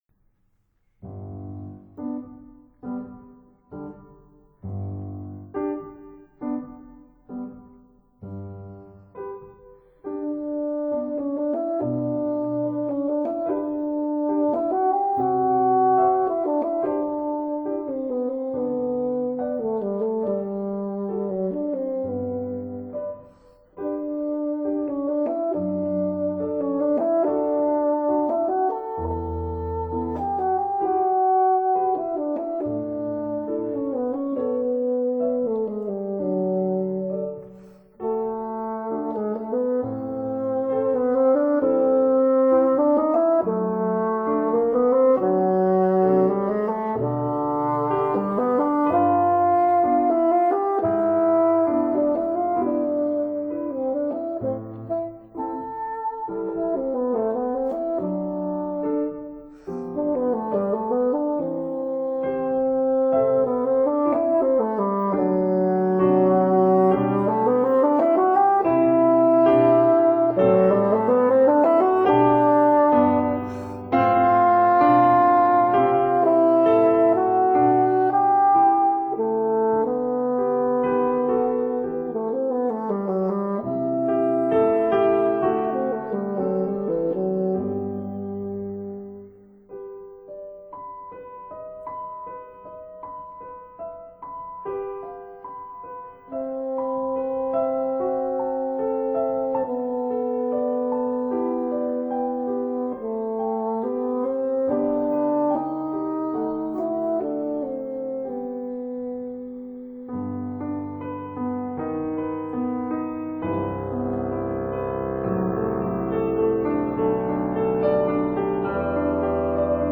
bassoon
piano